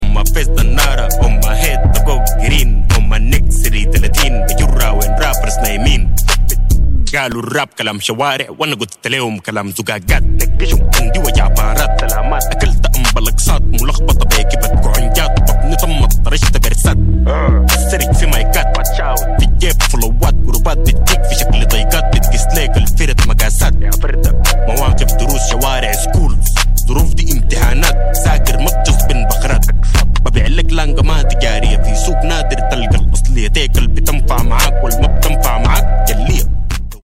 fire track...... new hip hop in the building.